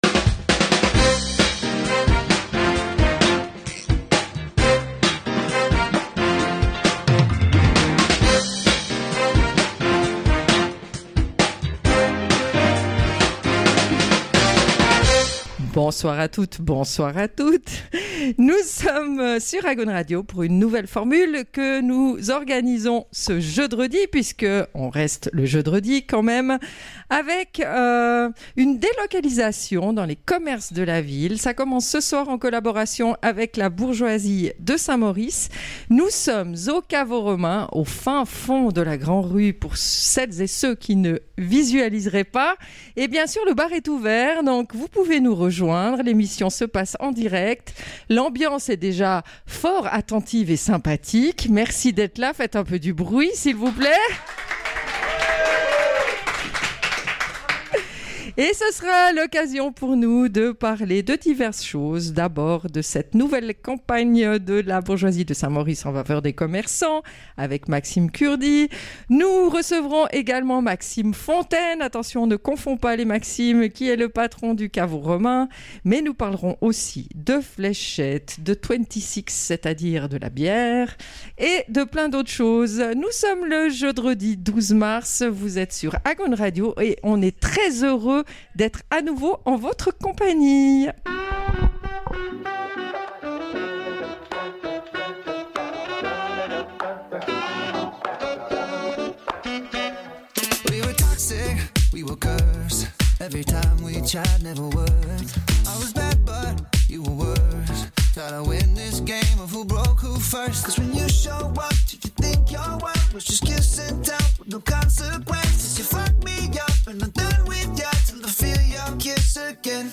Agaune Radio en direct du Caveau Romain à Saint-Maurice.